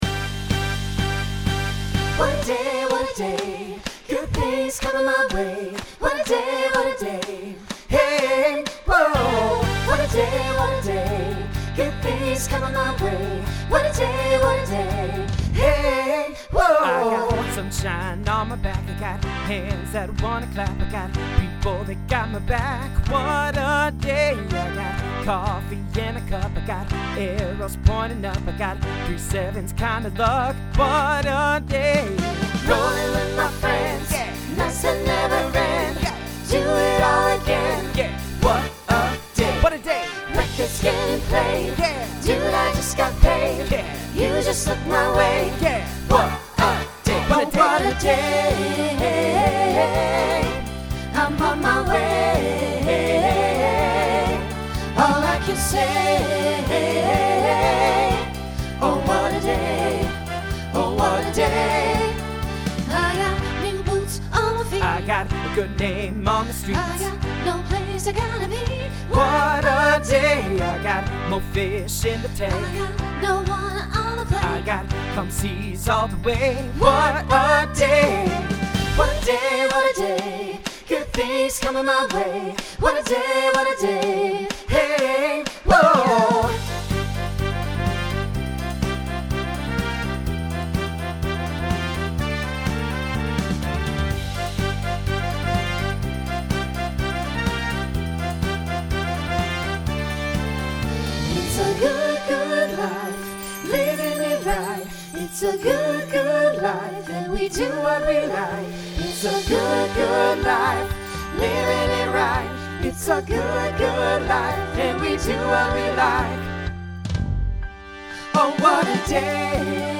Genre Rock
Voicing SATB